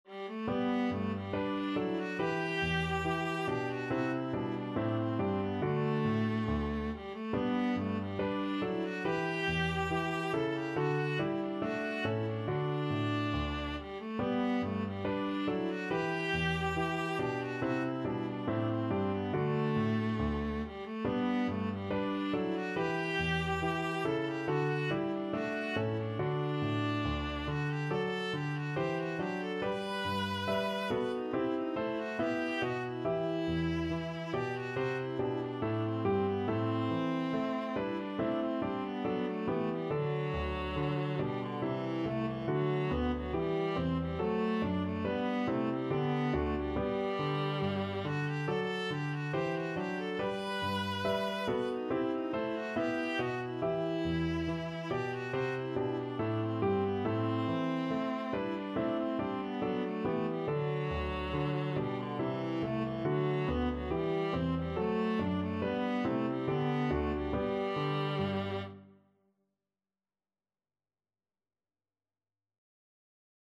Classical Handel, George Frideric Gavotte in G HWV 491 Viola version
Viola
G major (Sounding Pitch) (View more G major Music for Viola )
4/4 (View more 4/4 Music)
D4-B5
Classical (View more Classical Viola Music)